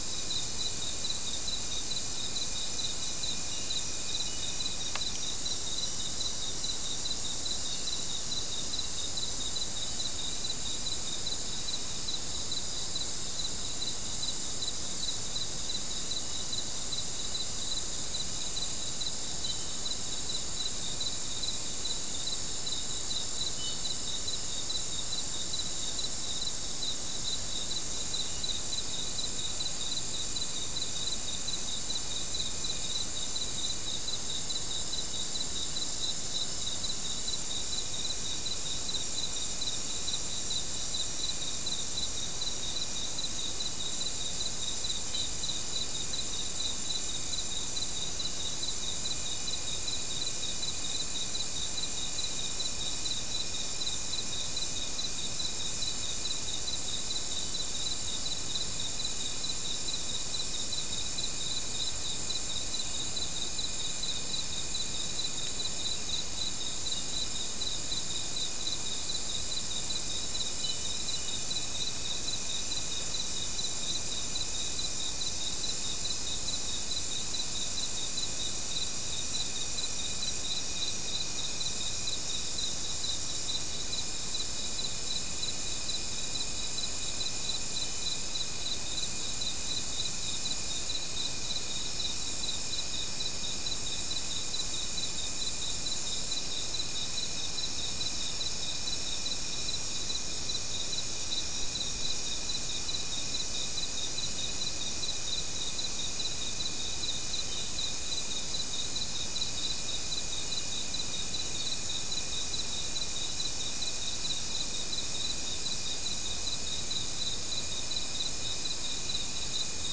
Non-specimen recording: Soundscape Recording Location: South America: Guyana: Sandstone: 3
Recorder: SM3